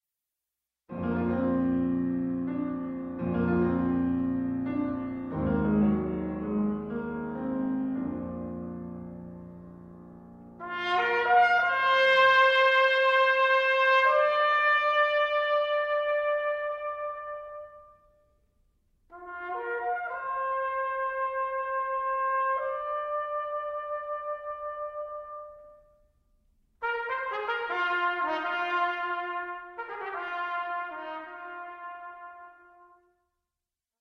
Trumpet
Piano.